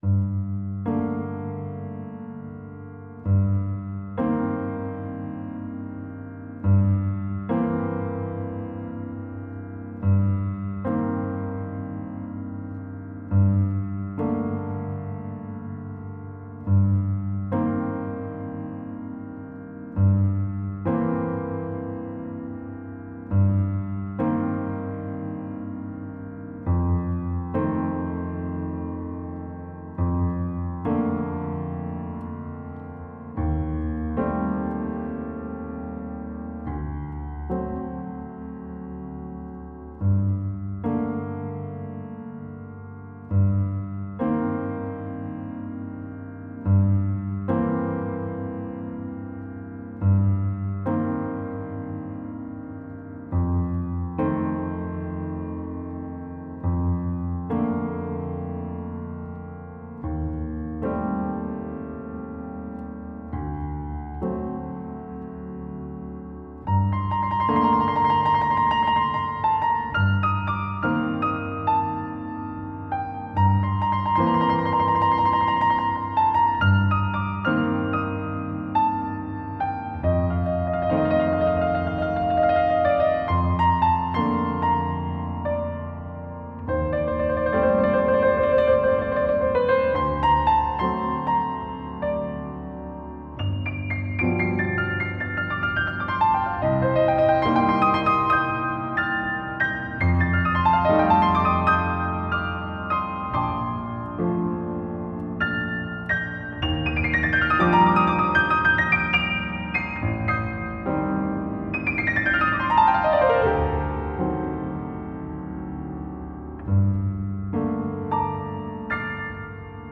Enregistrement audio piano seul